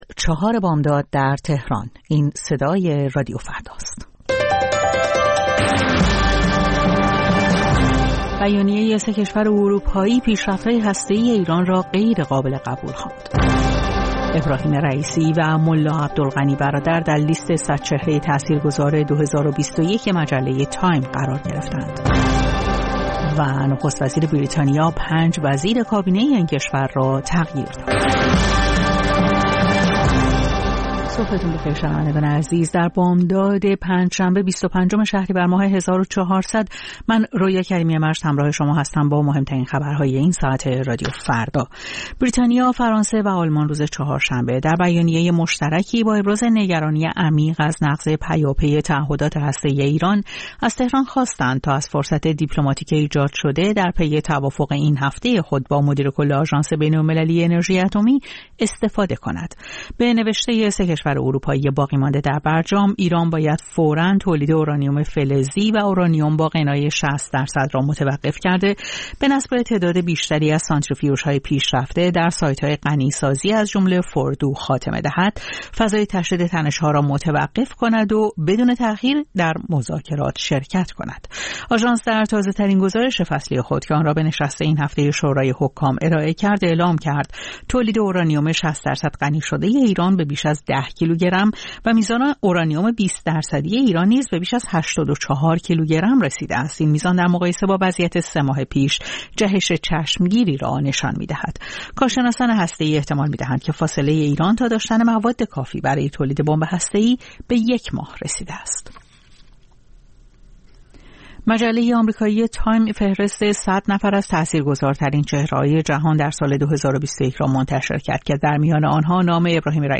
سرخط خبرها ۴:۰۰